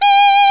Index of /90_sSampleCDs/Roland L-CD702/VOL-2/SAX_Alto Short/SAX_Pop Alto
SAX F#4 S.wav